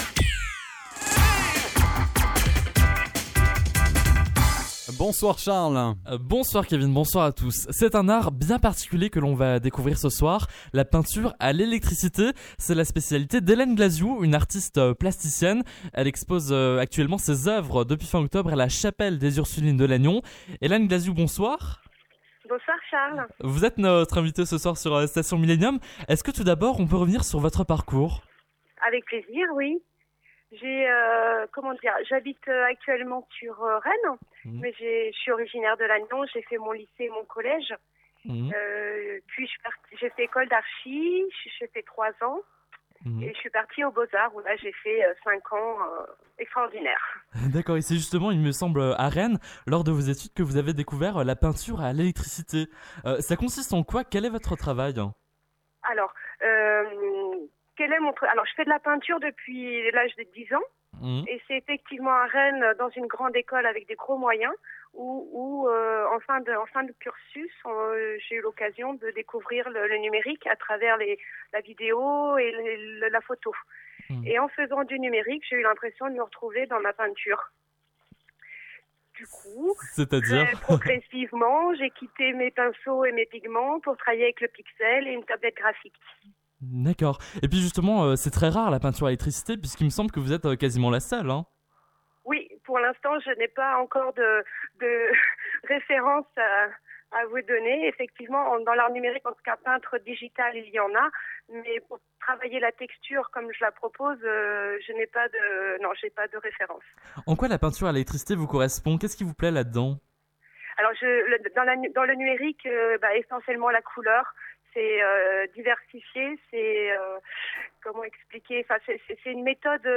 Interview Radio Lannion